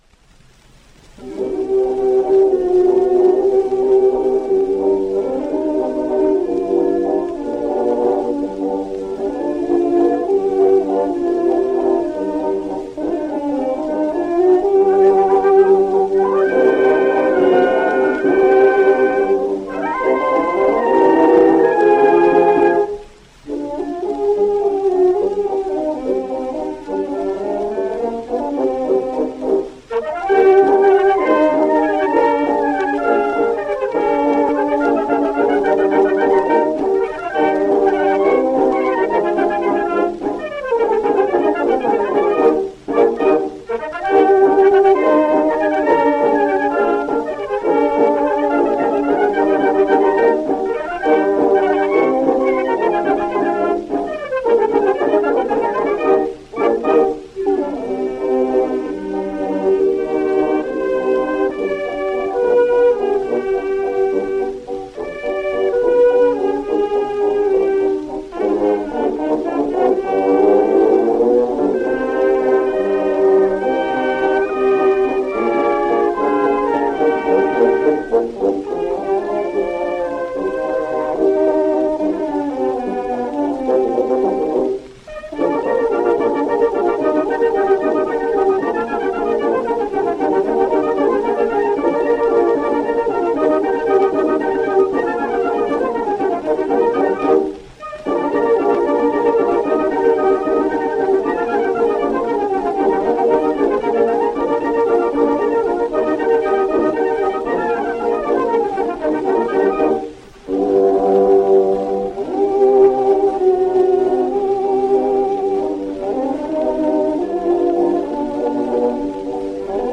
Справа — запись 1910-го года (скачать)